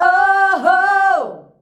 OOOHOO  G.wav